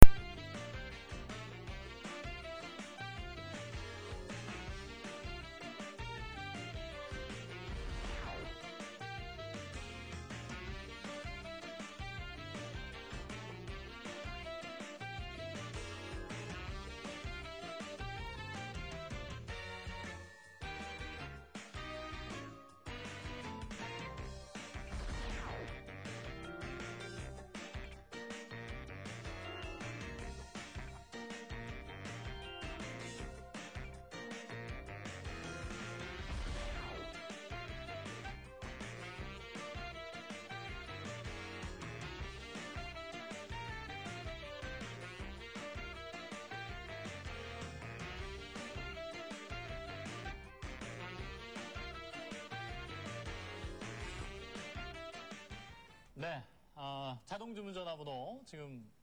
트롬본 연주가 참 멋스러워요
홈쇼핑채널서 흘러나온 신나는 곡!!!
2005-04-15 오전 2:12:00 퓨전 계열 음악인 거 같은데...